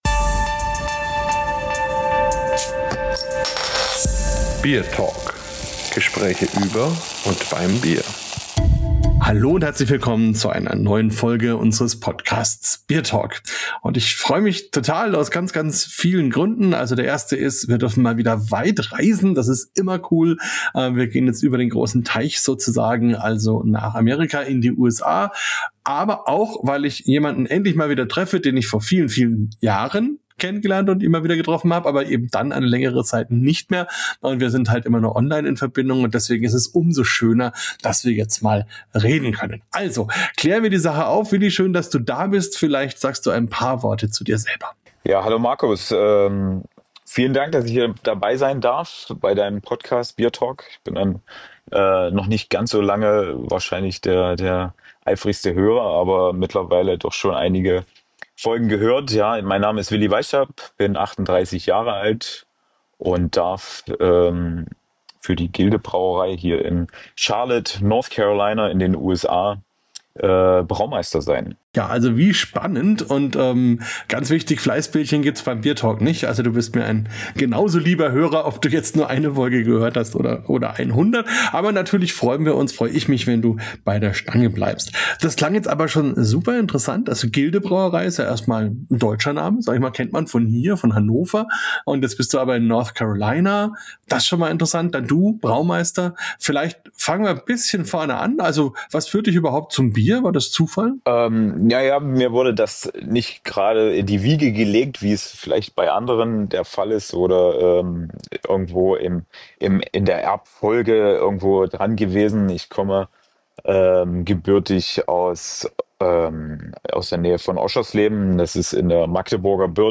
BierTalk Spezial 75 – Interview